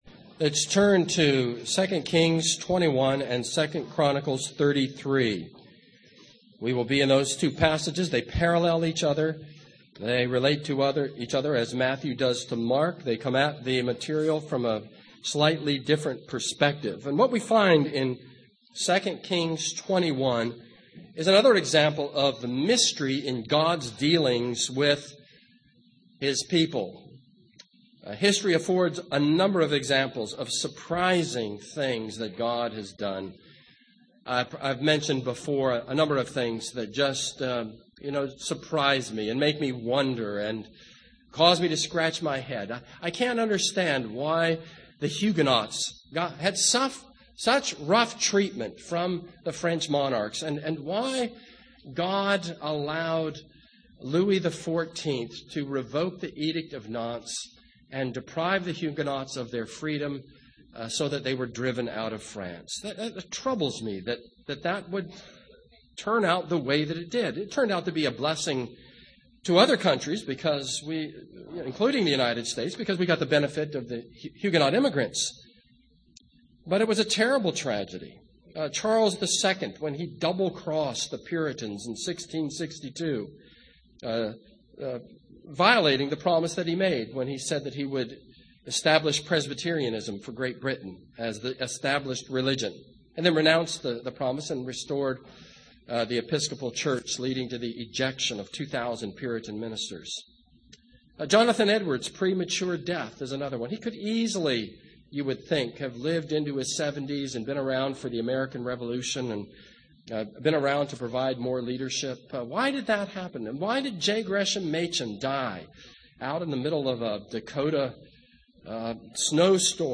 This is a sermon on 2 Kings 21.